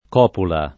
[ k 0 pula ]